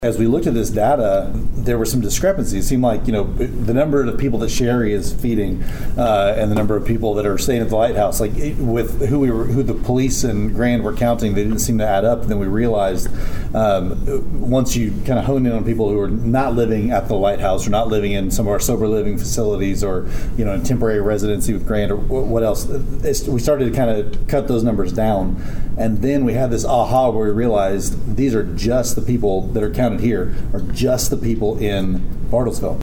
At Monday evening's city council meeting in Bartlesville, members of the homeless task force gave an update on their findings. City councilor Aaron Kirkpatrick serves on that task force and says